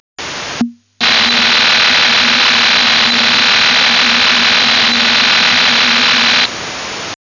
Ширина спектра (Band Width) Примерно 6000 Гц
Частота манипуляции (Baud Rate) 4000 Гц
n-Ary (PSK/MPSK) PSK-4 без переходов на 180 градусов
Частота несущей (Carrier frequency) 3000 Гц
Модуляция, в которой сигнал принят (RX mode) NFM
Сигнал спутника серии Компас, имеет судя по всему модуляцию аналогичную с Orbcomm, то есть SDPSK, но с другими параметрами.